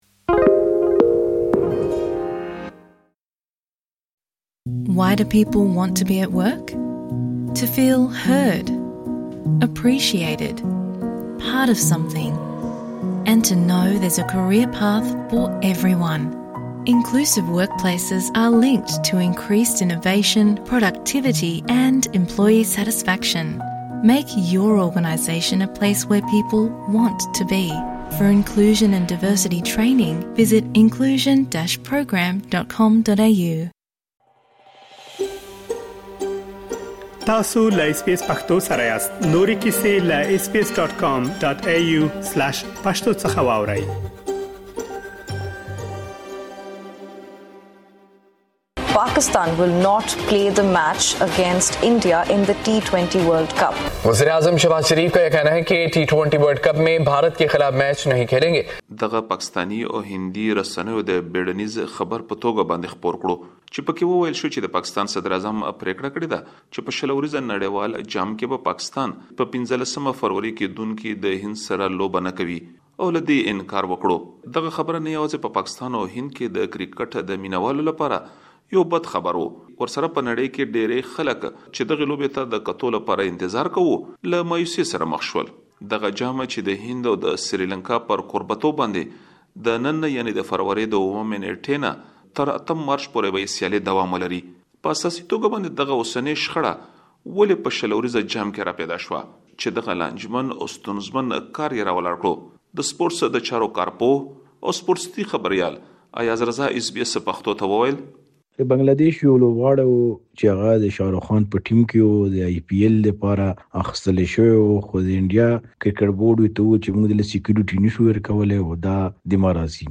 نړیوال جام کې له هند سره د پاکستان د نه لوبېدو پرېکړه؛ د خیبر پښتونخوا اوسېدونکي څه وايي؟